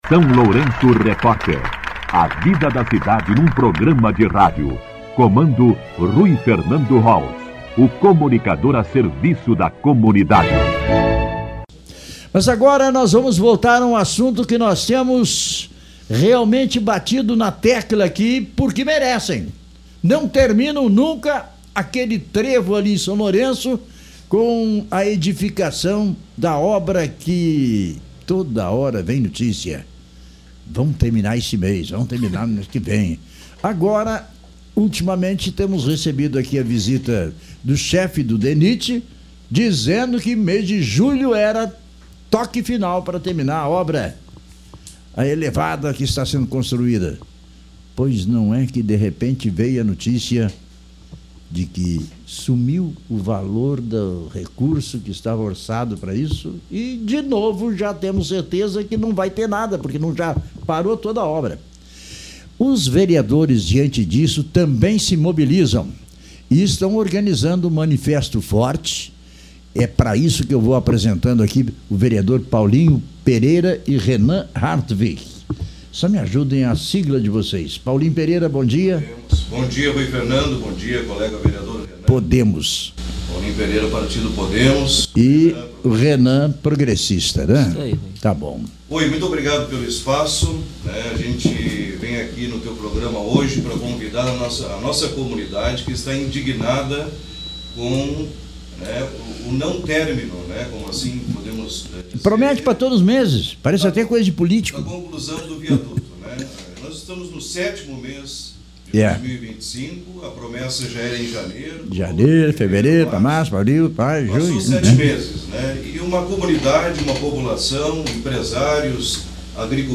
Entrevista com os Vereadores Paulinho Pereira (Podemos) e Renan Hartwig (PP)